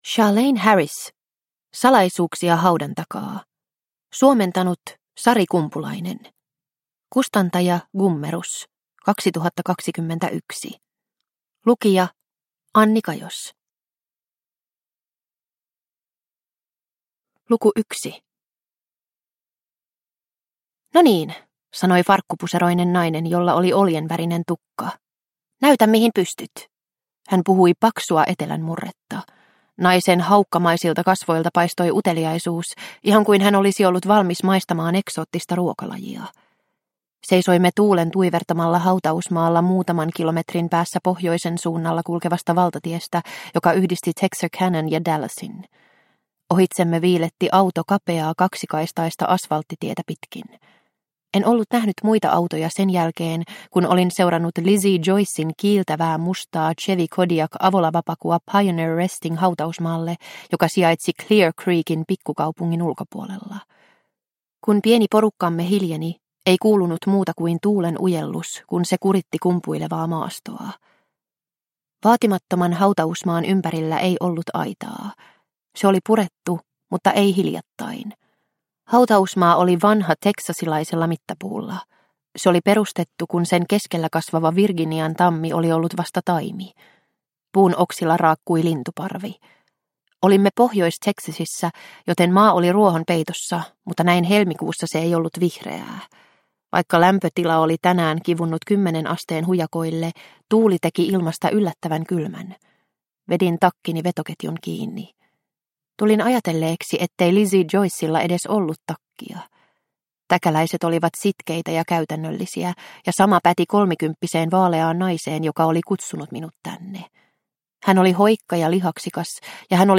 Salaisuuksia haudan takaa – Ljudbok – Laddas ner